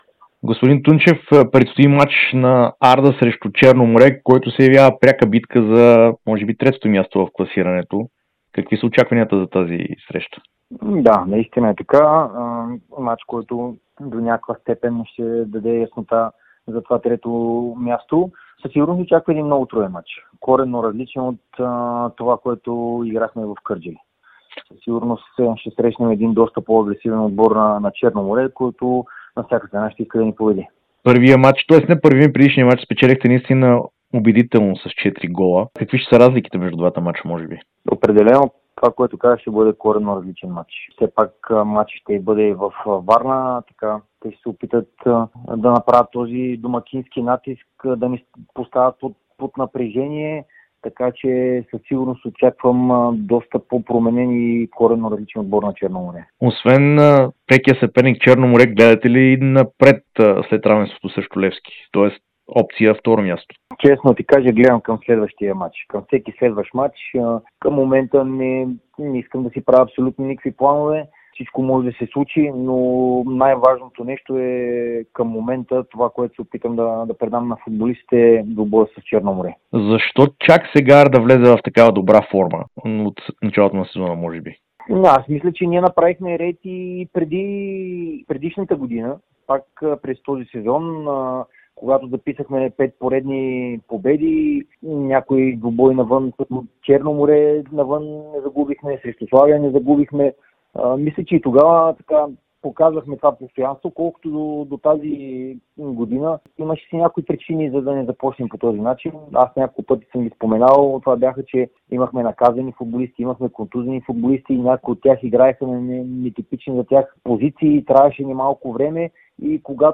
Старши треньорът на Арда Кърджали – Александър Тунчев, даде ексклузивно интервю за Дарик радио и dsport, в което сподели очакванията си преди ключовия двубой срещу Черно море във Варна. Срещата, която ще се играе тази неделя, е пряк сблъсък в битката за третото място в efbet Лига.